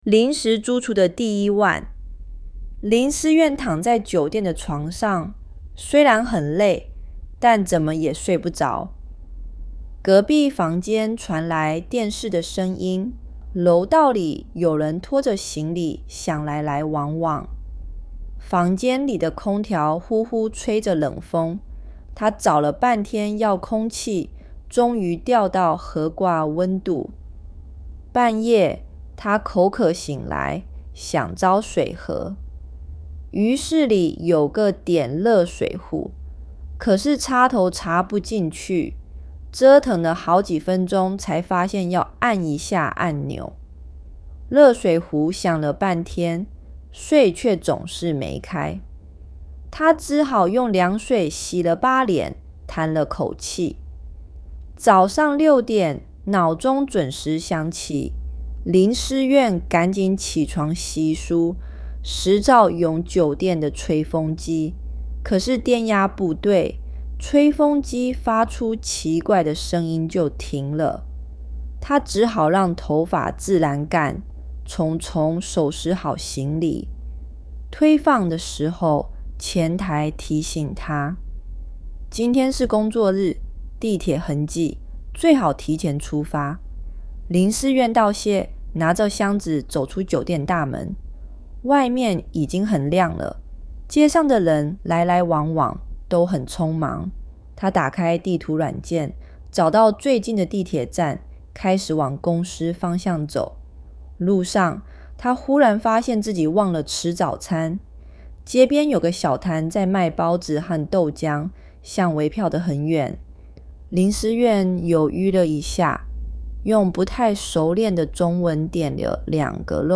• Before reading: Listen to understand rhythm, intonation, and natural speech.